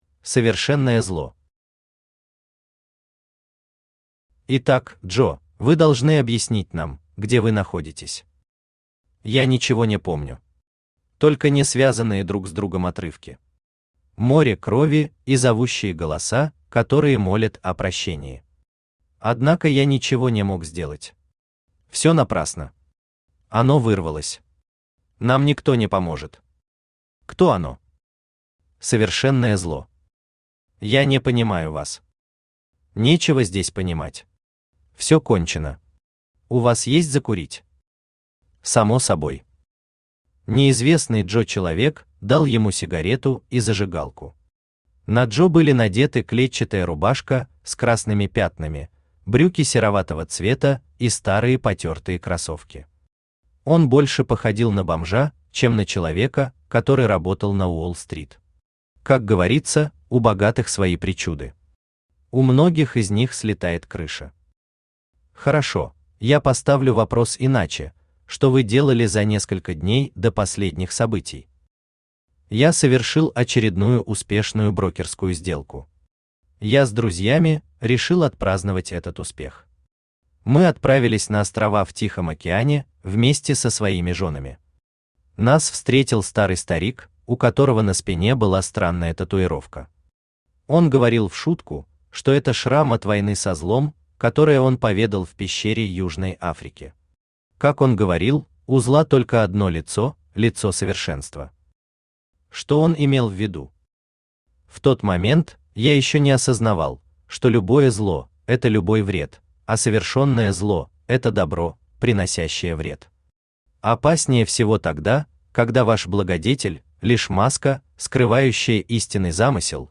Аудиокнига Неопределённость. Сборник рассказов | Библиотека аудиокниг
Сборник рассказов Автор Виталий Александрович Кириллов Читает аудиокнигу Авточтец ЛитРес.